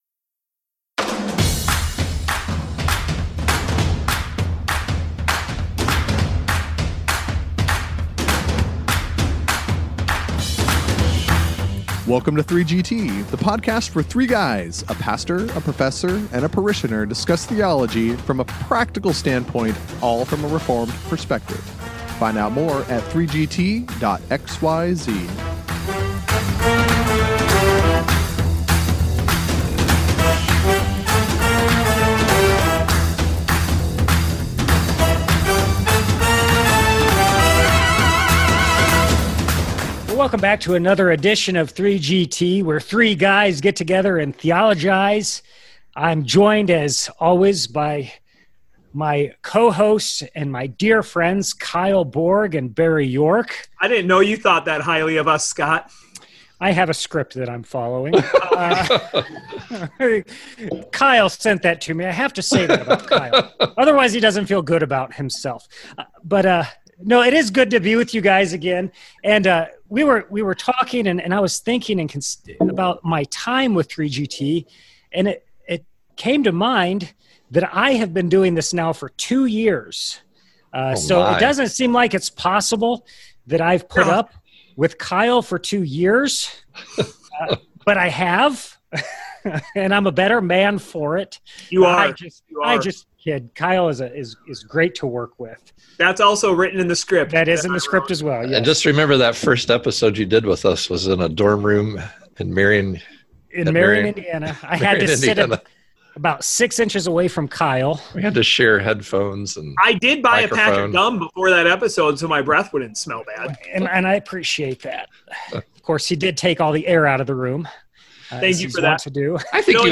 After congratulating the parishioner on a few anniversaries, the men turn their attention to the wisdom found in the book of Proverbs.